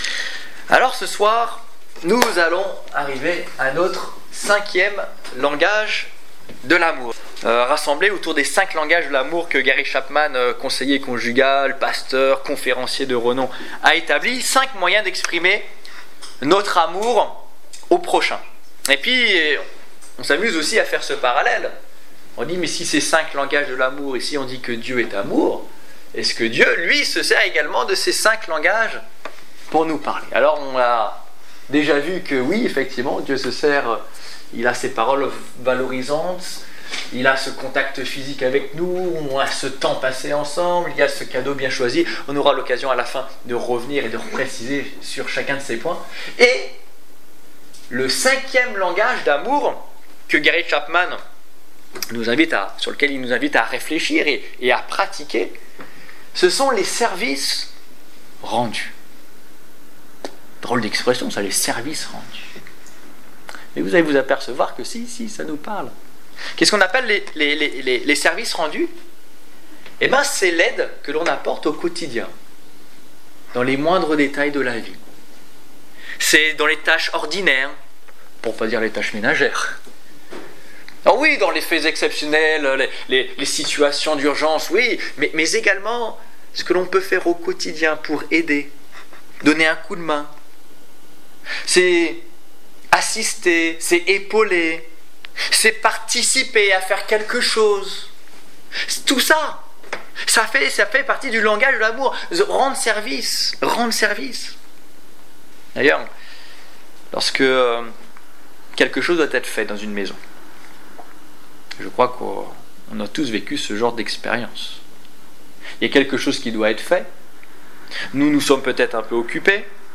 Les 5 langages de l'amour - Les services rendus Détails Prédications - liste complète Évangélisation du 6 mars 2015 Ecoutez l'enregistrement de ce message à l'aide du lecteur